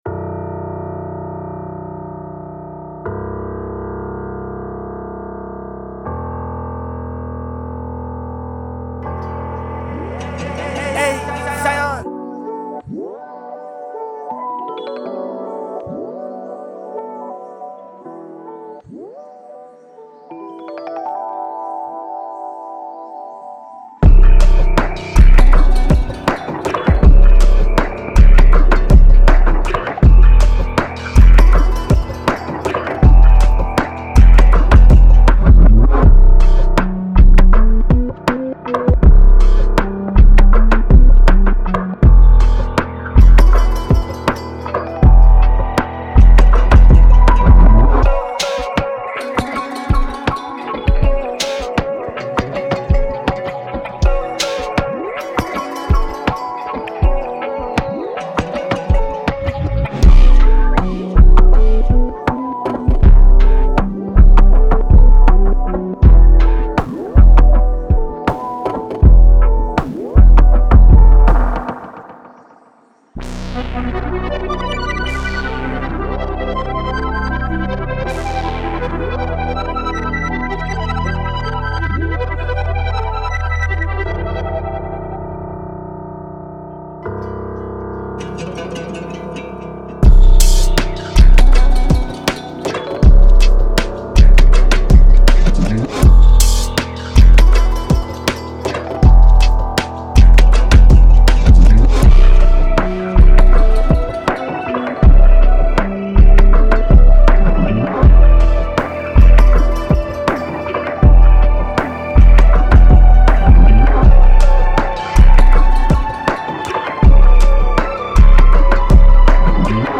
80 E Minor